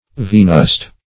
Search Result for " venust" : The Collaborative International Dictionary of English v.0.48: Venust \Ve*nust"\ (v[-e]*n[u^]st"), a. [L. venustus, from Venus the goddess of love.]